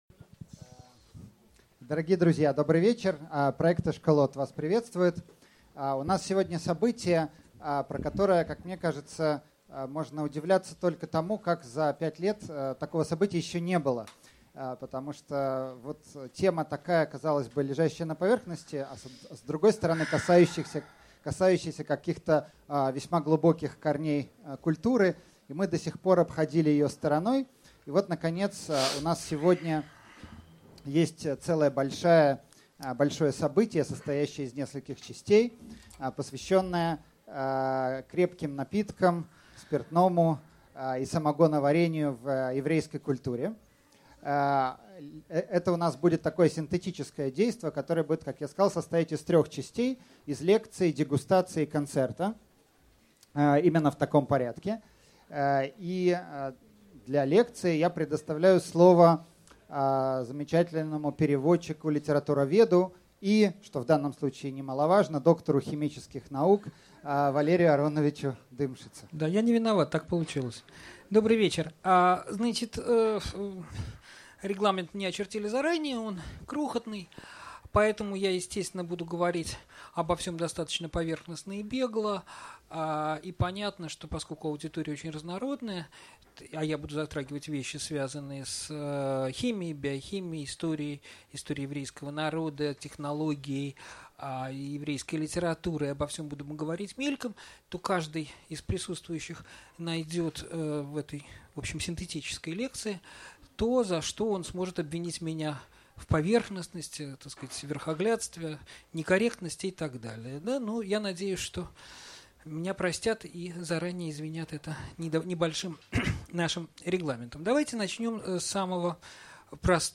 Аудиокнига Самогоноварение в еврейской культуре | Библиотека аудиокниг